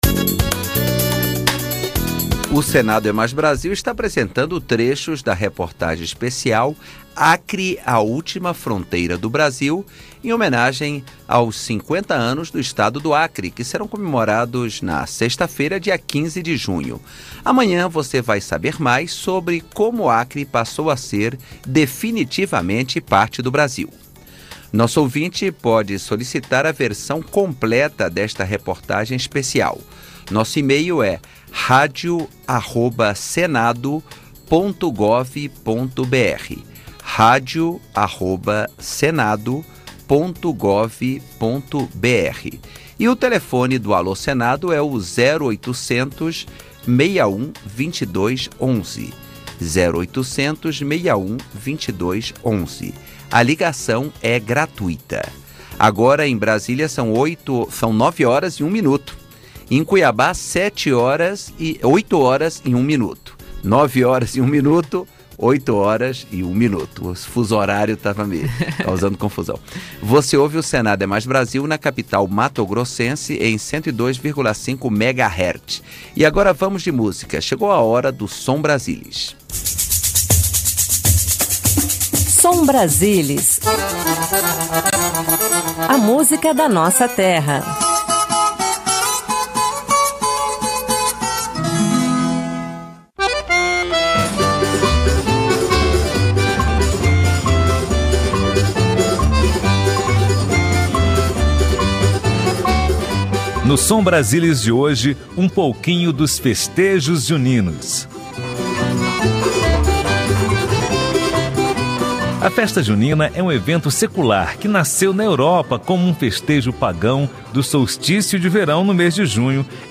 Entrevista com o senador Casildo Maldaner (PMDB-SC)